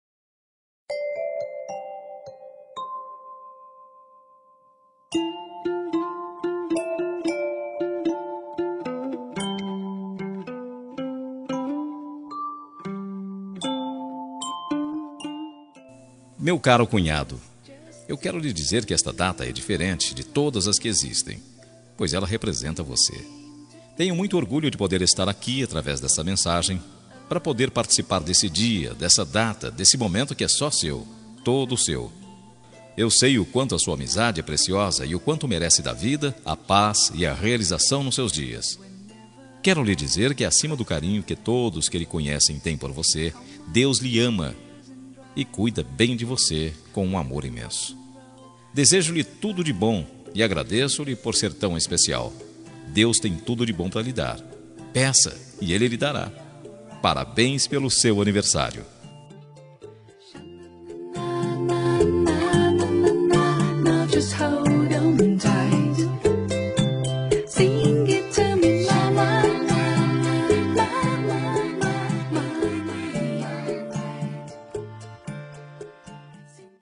Aniversário de Cunhado – Voz Masculina – Cód: 5233